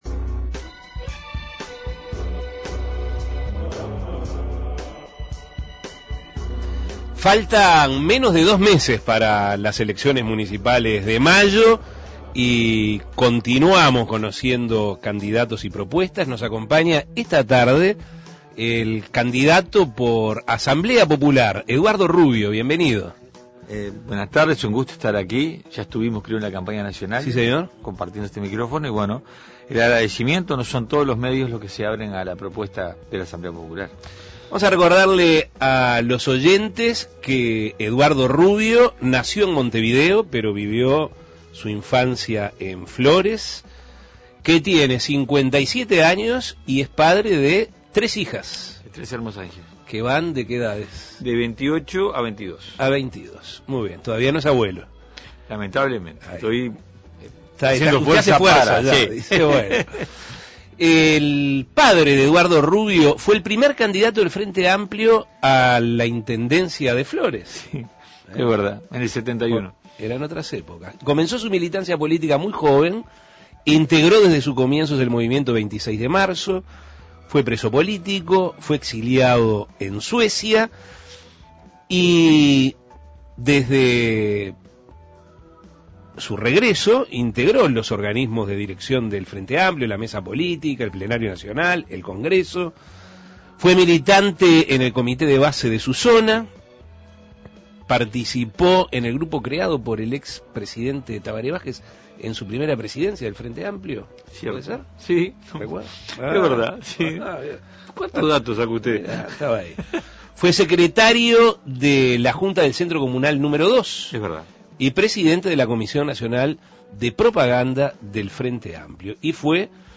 El candidato a la Intendencia de Montevideo por Asamblea Popular, dialogó sobre sus propuestas y cómo piensa implementar sus ideas en la capital, las condiciones de pobreza que viven algunos sectores de la población montevideana tras 20 años de administración del Frente Amplio, la descentralización, la fiscalización de las patentes en el departamento y realizó una evaluación sobre la gestión que está por culminar. Escuche la entrevista.